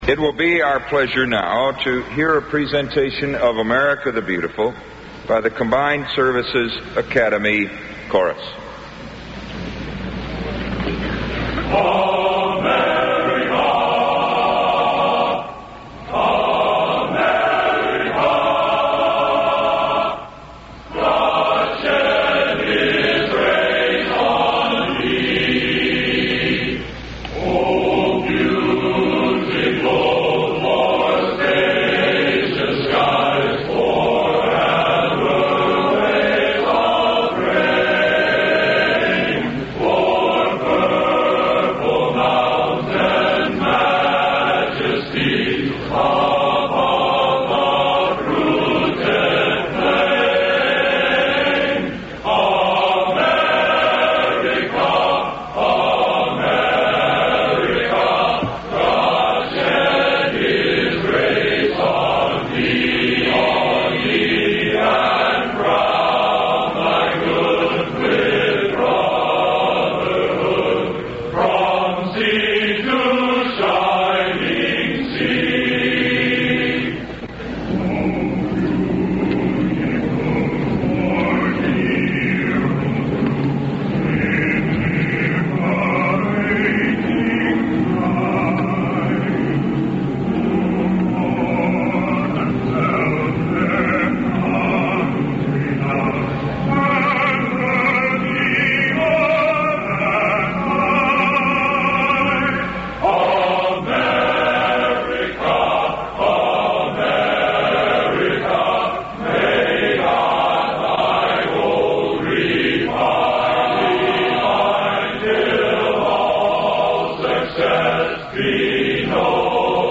The oath of office is administered by Chief Justice Warren Burger, to U.S. President Richard Nixon, followed by the president's inaugural address. Preceded by a performance of America the Beautiful by The Combined Services Academy Chorus.